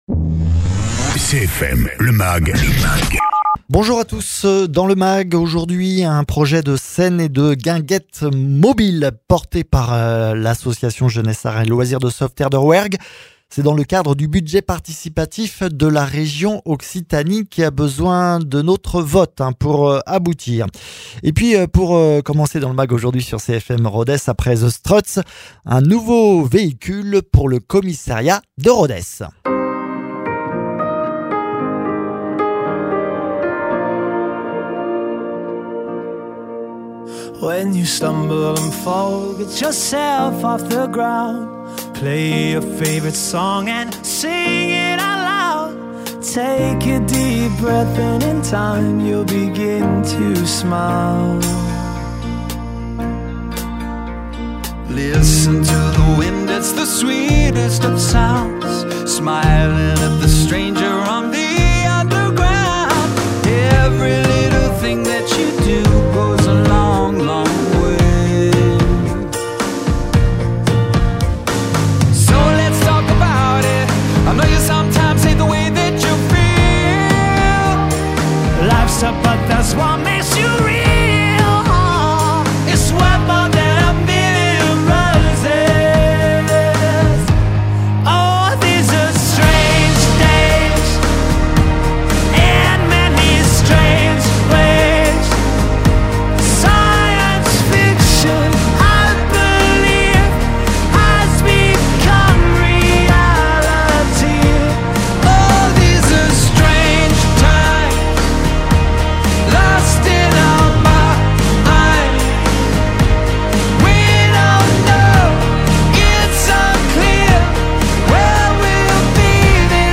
Invité(s) : Valérie Michel Moreau, préfète ; Loïc Jezequel, Directeur départemental de la sécurité public de l’Aveyron